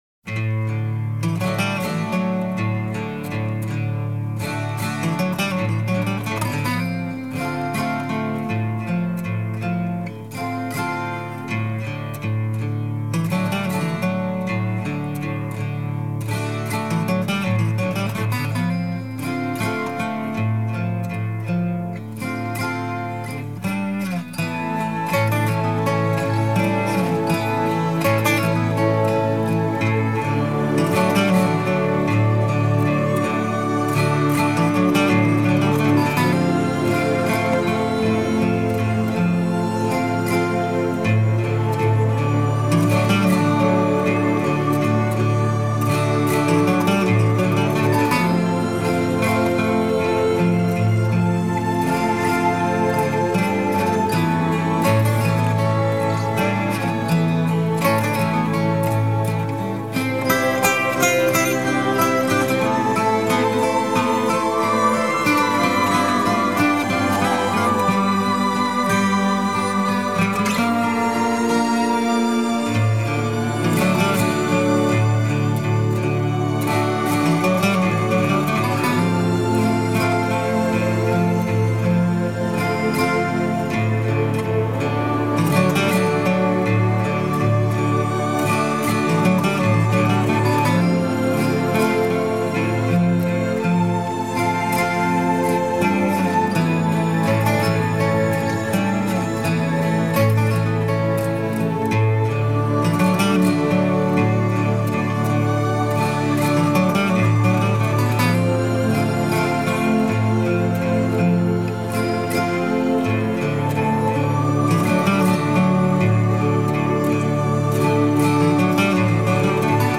The twelve-string guitar.
like a harpsichord.
The strings.